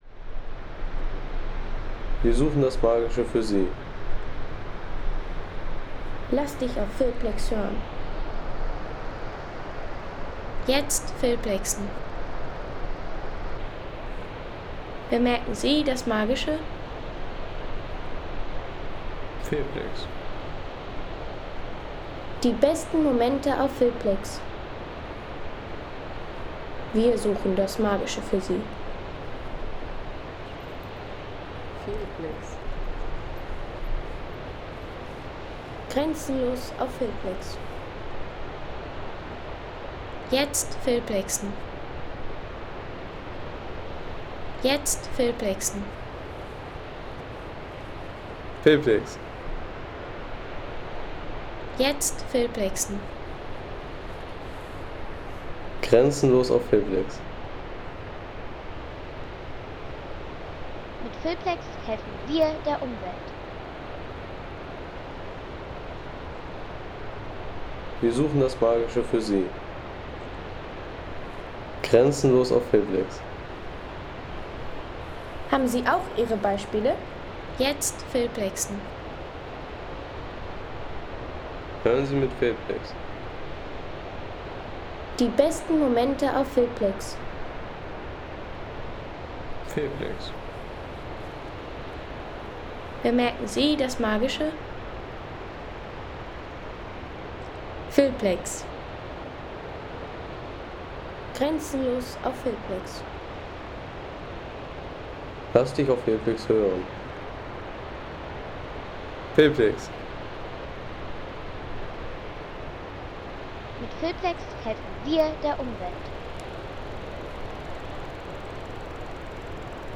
Osttiroler Gschlösstal Soundeffekt | Alpine Talatmosphäre
Natürliche Alpenatmosphäre aus dem Osttiroler Gschlösstal mit Wasserfällen, Wiesenruhe und entfernten Kühen.
Bringe die Ruhe eines Alpentals mit Wasserfällen, grünen Wiesen und friedlicher Weidestimmung in Filme, Reiseclips und kreative Szenen.